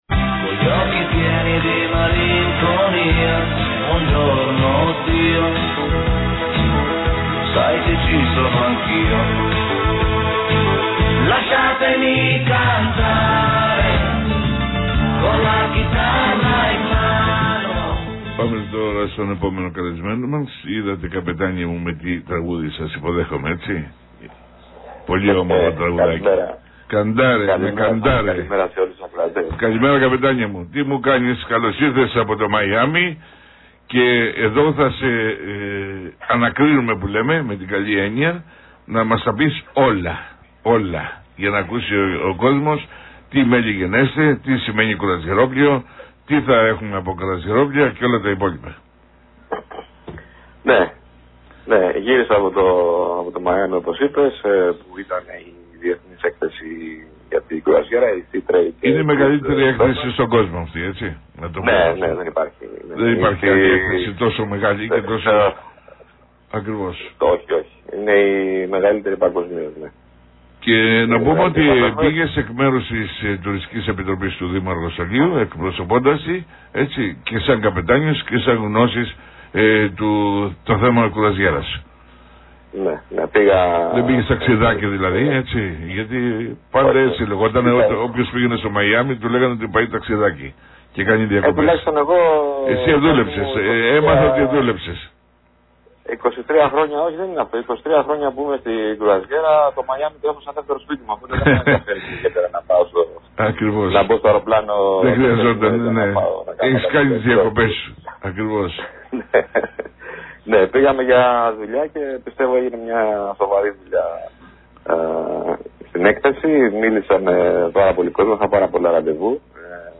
Εκπομπή το Βημα του Πολίτη Νησί 93,9 fm 3/4/2023 – Ανεξάρτητος